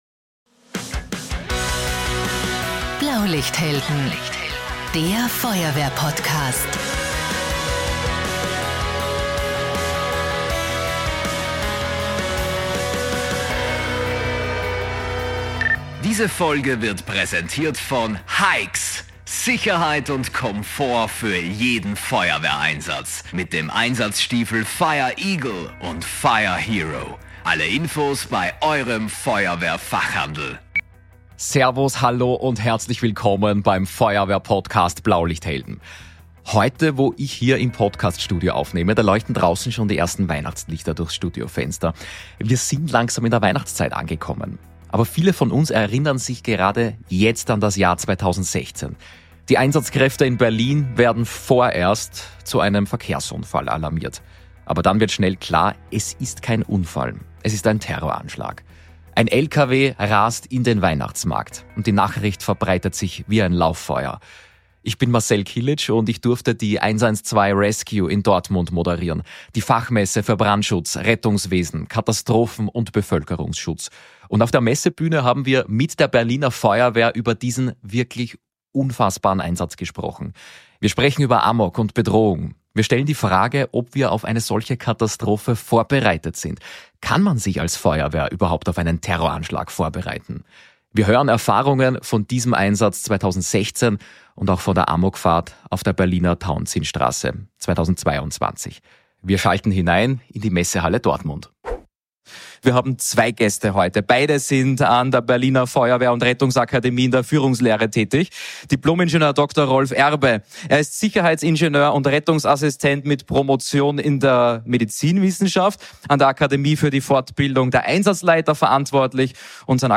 Diese Folge über die Erfahrungen aus dem Anschlag am Berliner Weihnachtsmarkt (2016) und der Amokfahrt Tauentzienstraße (2022) haben wir bei der 112RESCUE in Dortmund aufgezeichnet.